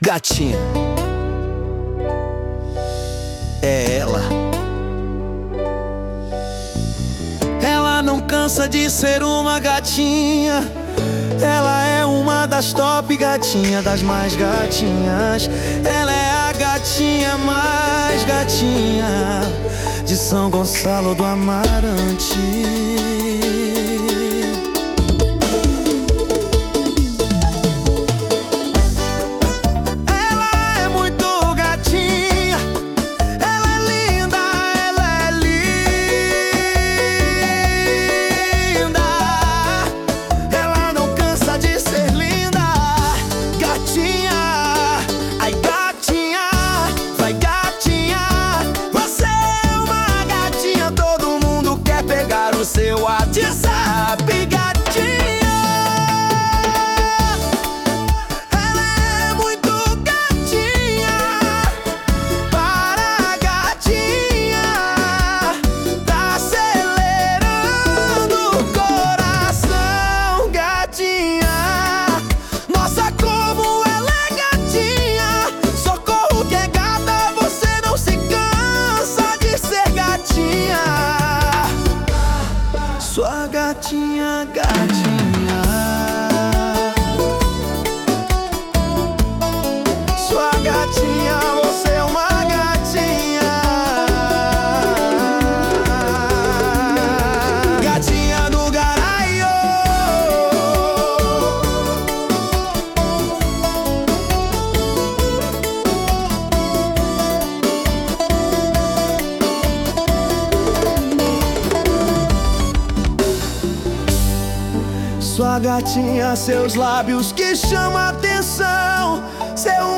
Versão Funk 1